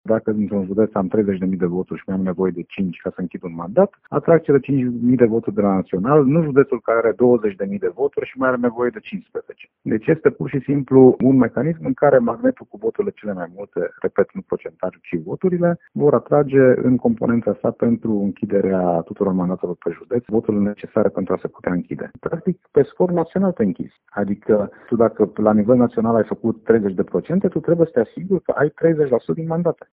La rândul său, deputatul PNL, Ben Oni Ardelean, susține că sistemul actual este echitabil, pentru că lucrurile se echilibrează la nivel național.